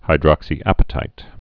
(hī-drŏksē-ăpĭ-tīt)